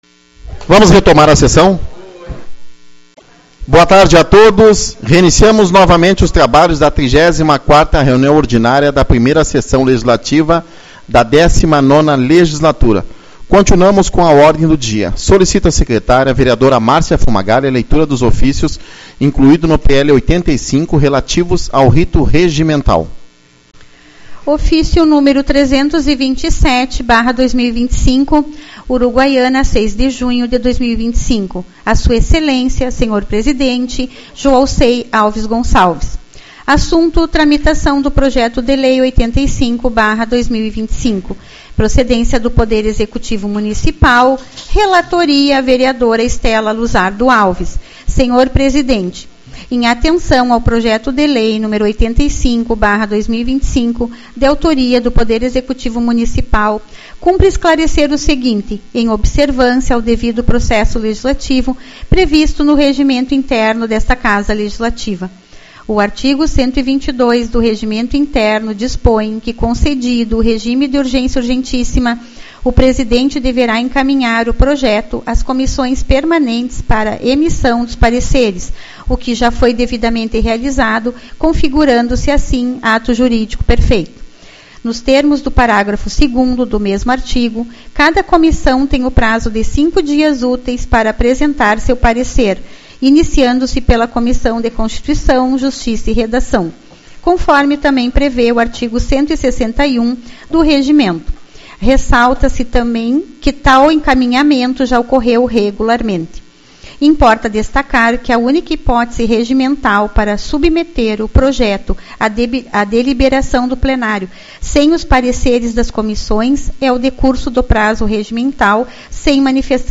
06/06 - Reunião Ordinária